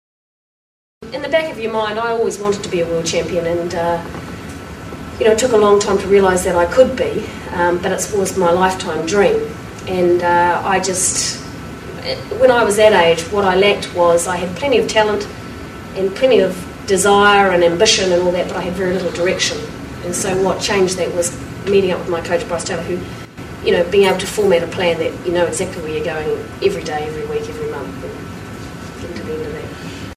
These sound tracks were produced from videos made at each of the Peak Performance Seminars.
Video and audio quality is not good for all events due to local venue conditions.